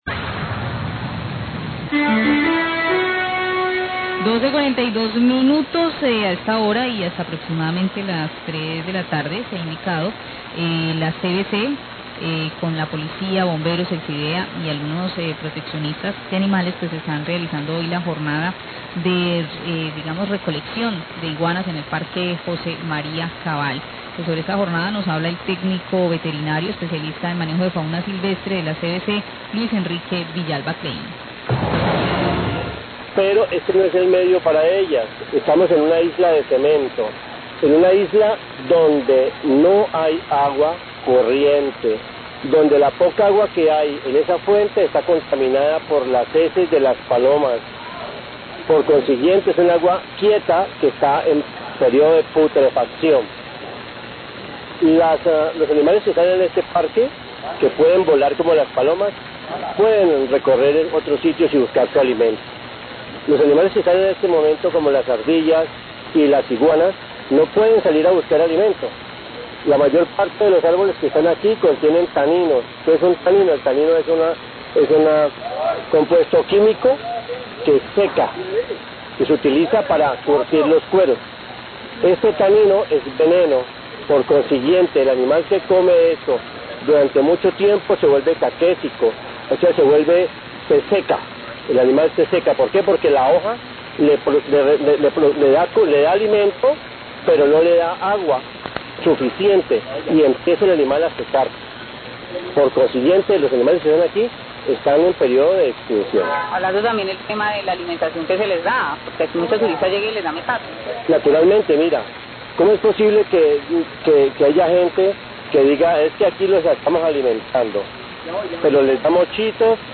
Radio
A esta hora con Asesoría Zootécnica Especializada de funcionarios de CVC de Cali, en el marco del 'Día del no carro y no moto',  inicia el operativo de captura y control de iguanas en el Parque Cabal, para ser devueltas a su hábitat correcto. Funcionario de la entidad ambiental se refiere a dicha jornada.